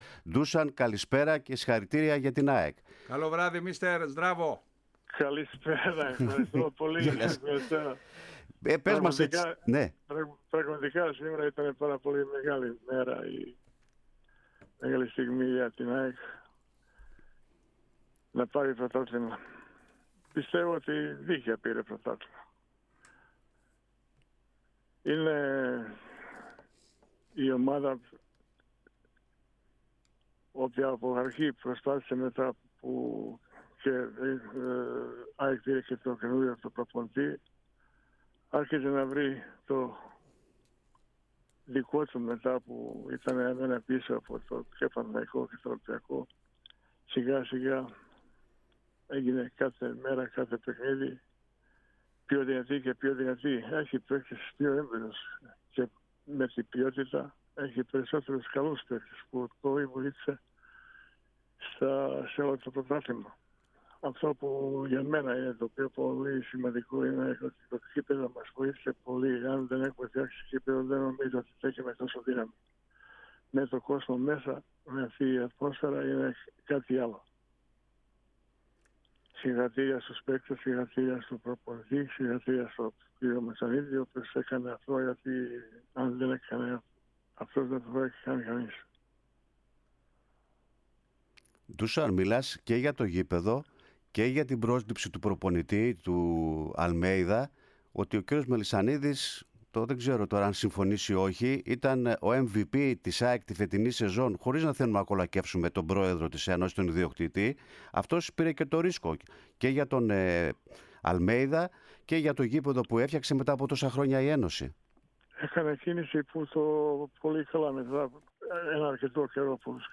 Ο Ντούσαν Μπάγεβιτς εμφανώς χαρούμενος για την κατάκτηση του πρωταθλήματος από την ΑΕΚ και με το χαμόγελο στα χείλη μίλησε στην ΕΡΑ ΣΠΟΡ, αποθεώνοντας παίκτες, προπονητές και… Δημήτρη Μελισσανίδη, ενώ τόνισε πως σε ενδεχόμενη κατάκτηση του νταμπλ ο σύλλογος θα μεγαλώσει κι άλλο.